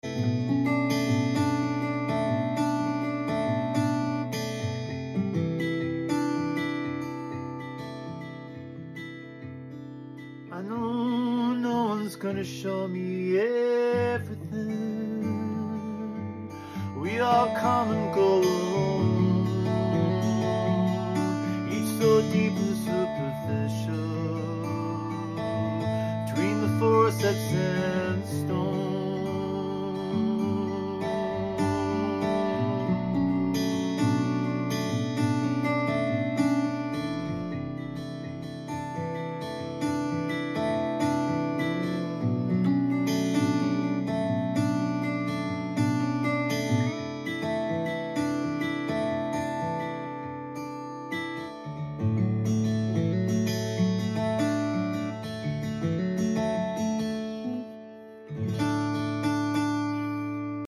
I know, this creature looks and sounds like a guitar tonight but he is a shapeshifter from tomorrow